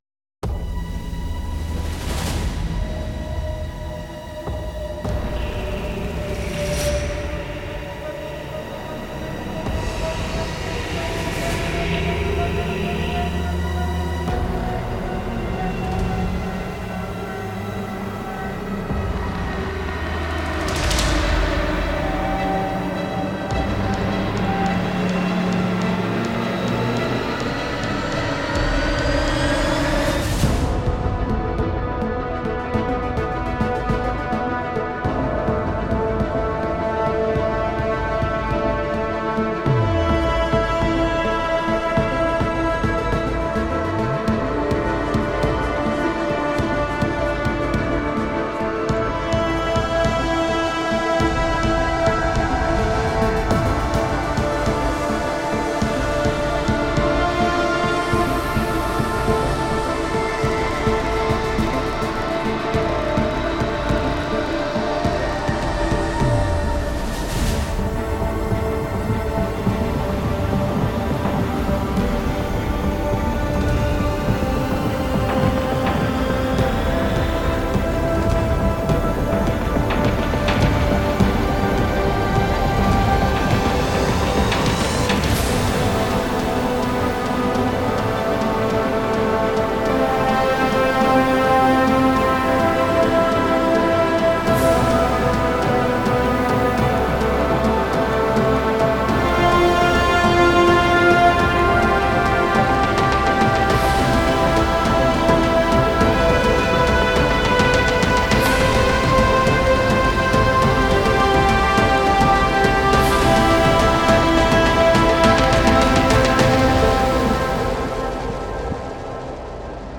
Hybrid epic track for trailers.
Hybrid epic track for trailers and cinematic.